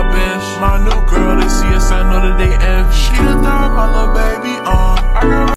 recoded in computer, bad quality😭